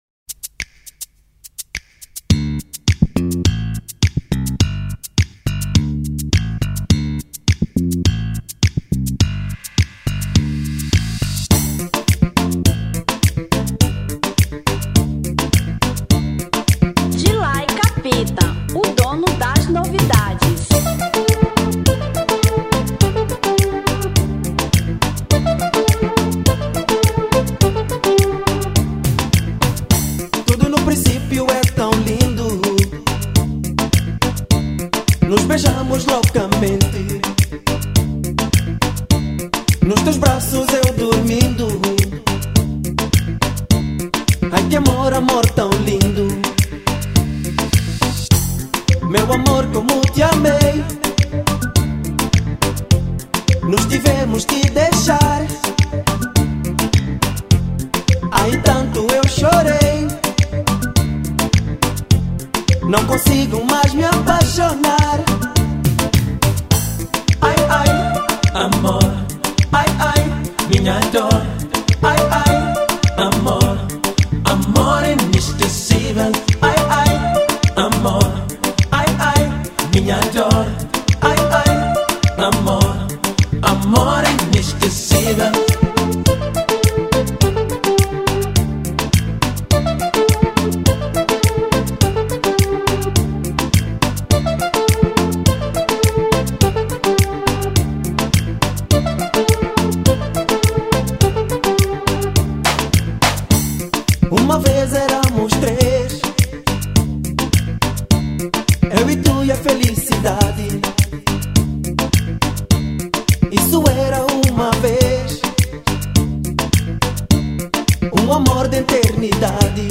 Kizomba 1996